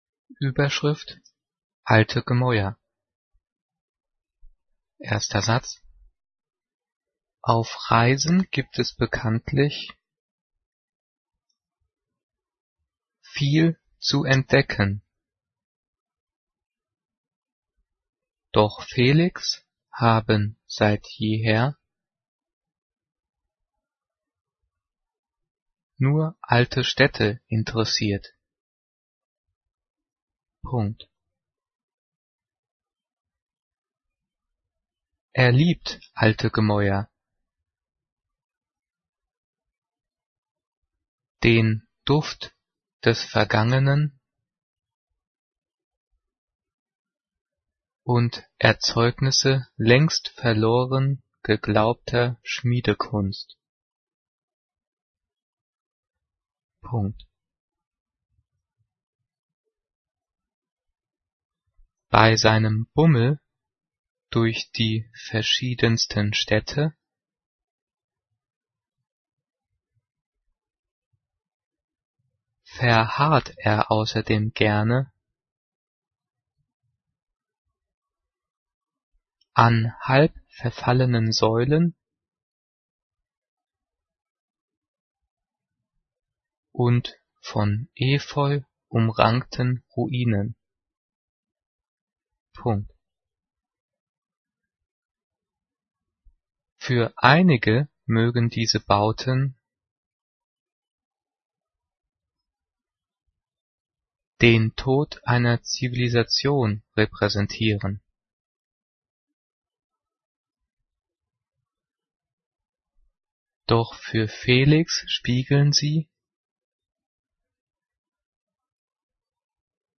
Diktiert: